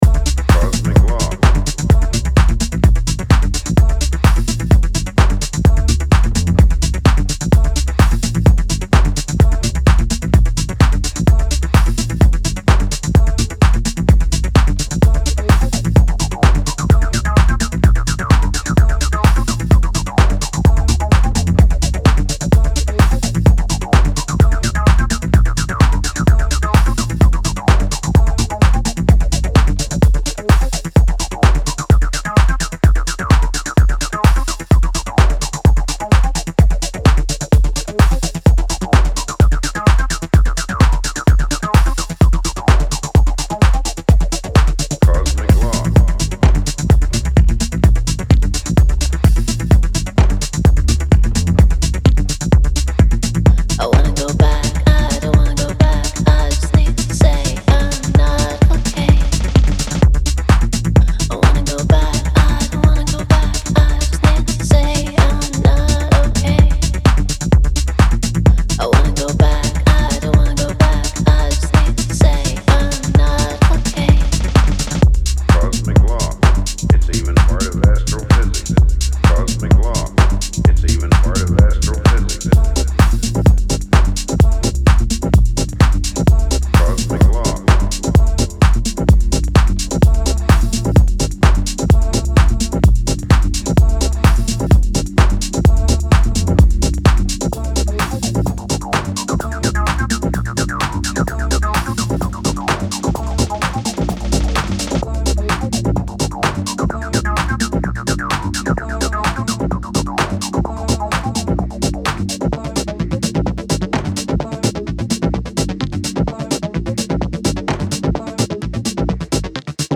いずれもストイックに研ぎ澄まされたグルーヴ